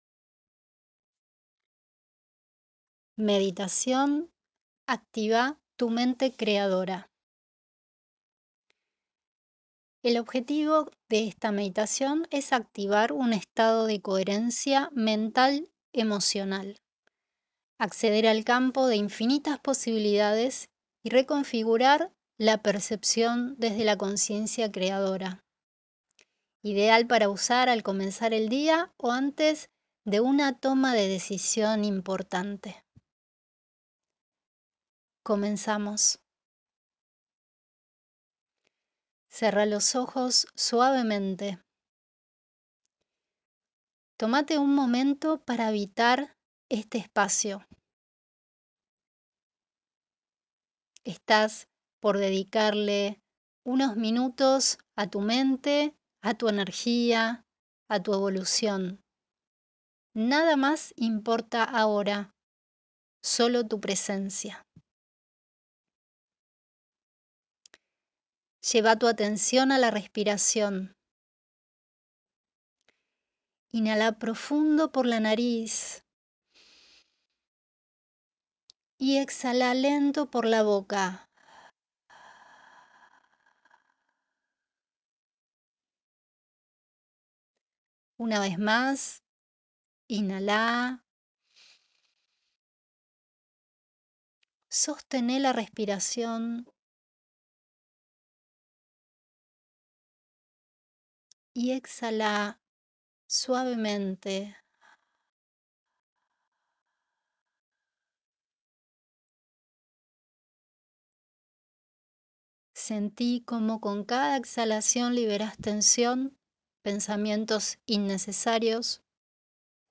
Esta meditación guiada está diseñada para ayudarte a: